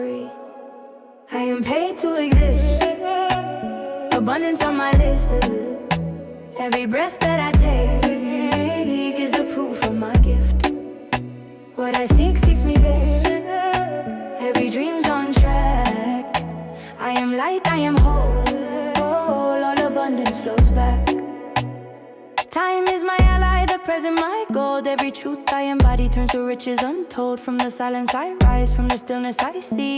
Жанр: Поп музыка / Электроника
Pop, Electronic, Downtempo